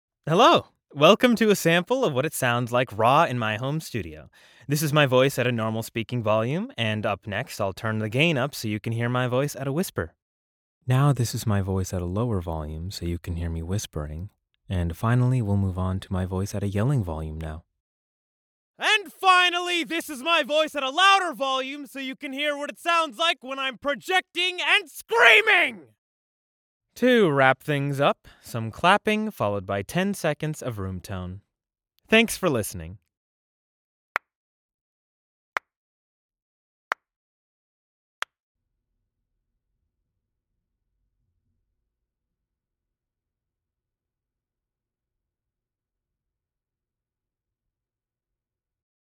Raw Studio Sample
Acoustically treated Vocal Booth
Neat King Bee Microphone